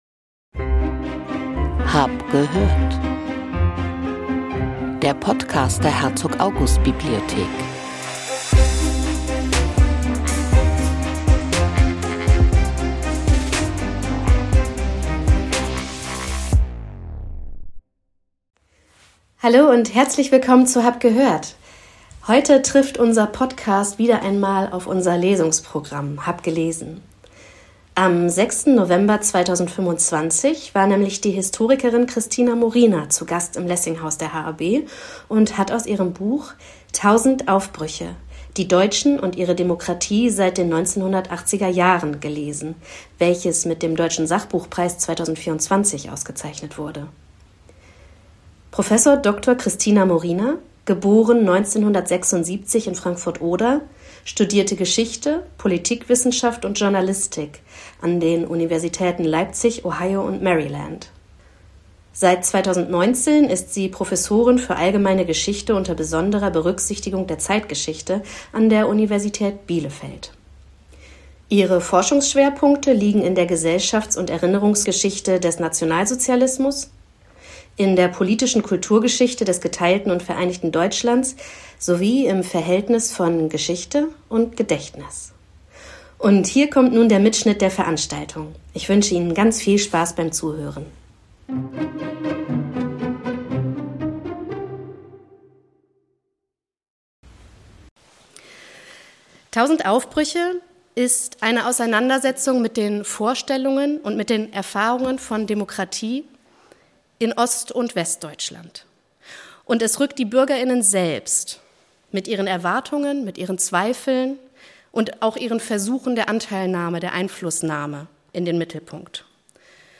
Die Lesung fand am 23. Oktober 2025 im Gartensaal des Lessinghauses der Herzog August Bibliothek statt.